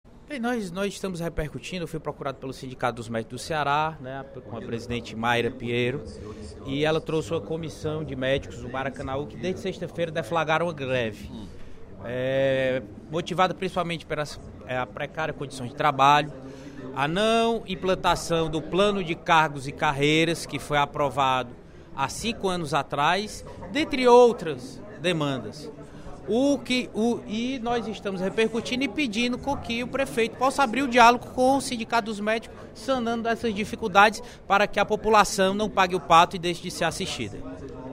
O deputado Julinho (PDT) cobrou, durante o primeiro expediente da sessão plenária desta terça-feira (09/05), uma solução para a situação dos profissionais médicos do hospital municipal de Maracanaú. Segundo ele, os médicos estão trabalhando sem os recursos necessários e, por isso, entraram em greve.